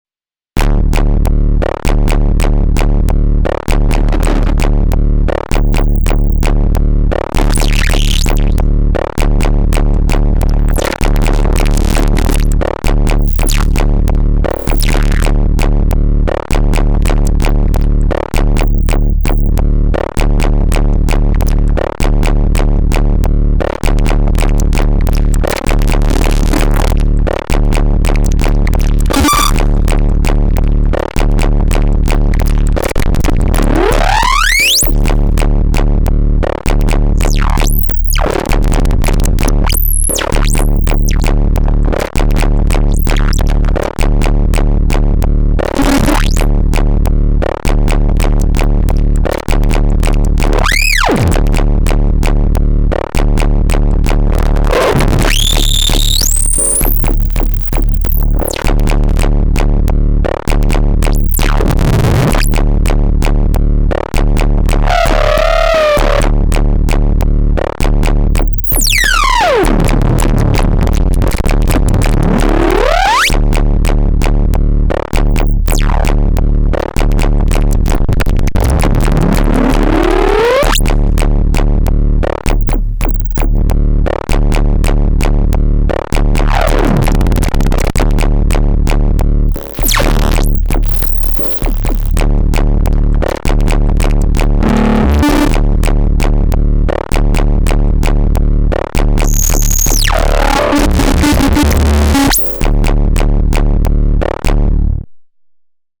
quick noise therapy session [loud :warning:]